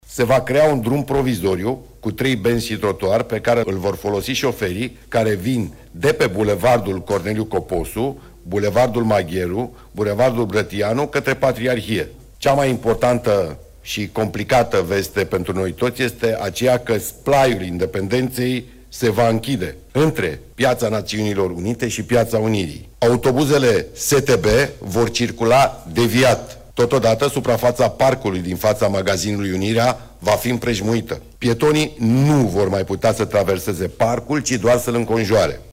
Pe 10 iunie încep lucrările la podul peste râul Dâmbovița, după ce Nicușor Dan a semnat, în ultima zi de mandat ca primar al Capitalei, autorizația de construire, singurul document de care mai era nevoie pentru începerea lucrărilor, a anunțat primarul sectorului 4, Daniel Băluță.